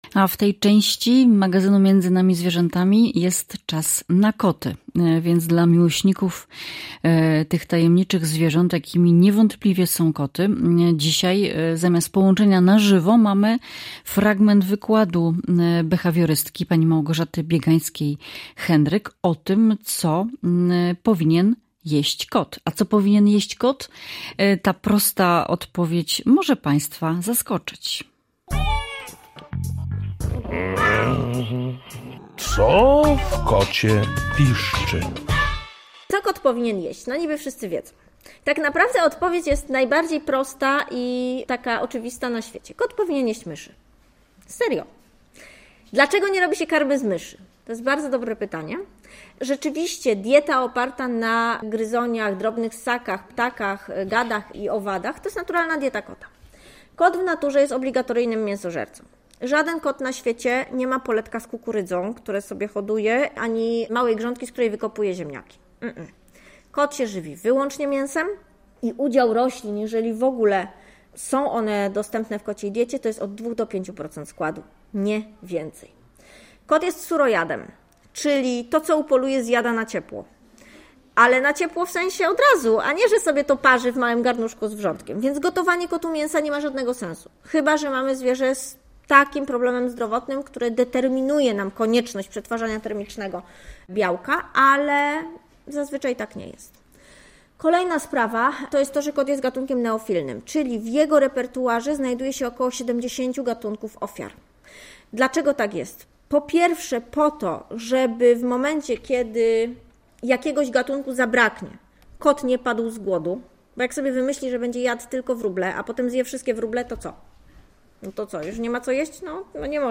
Dźwiękoławki - Radio Zachód, Lubuskie